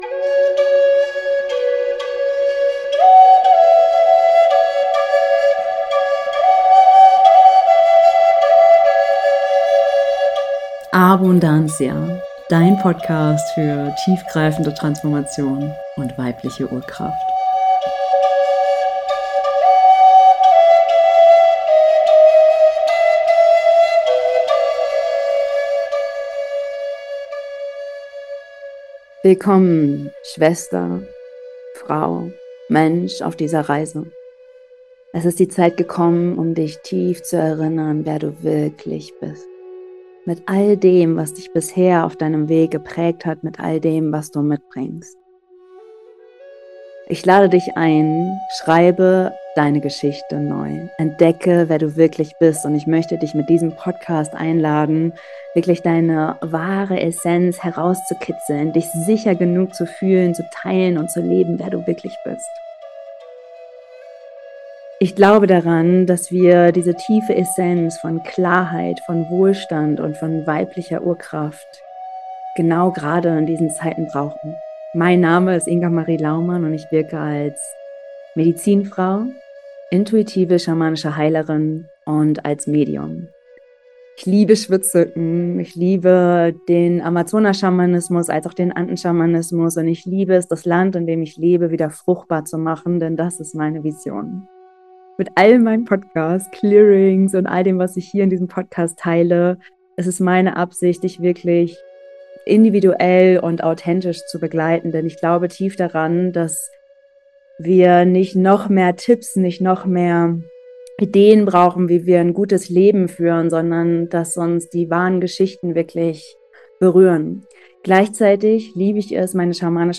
4# Öffne dich für neue Möglichkeiten - Upgrade für dein Leben, anstatt ein ständiges Downgrade - Meditation & Clearing ~ ABUNDANCIA - Dein Podcast für weibliche Urkraft & tiefgreifende Transformation!